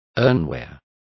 Complete with pronunciation of the translation of earthenware.